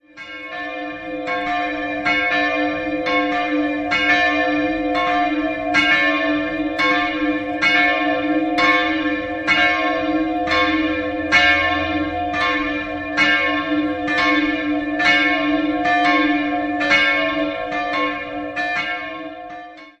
Von der Innenausstattung ist vor allem eine Mondsichelmadonna aus der Zeit um 1500 erwähnenswert. 2-stimmiges Geläute: c''-d'' Die größere Glocke wurde 1778 von Matthias Stapf in Eichstätt gegossen, die kleinere Anfang des 16. Jahrhunderts von Sebald Beheim (I) in Nürnberg.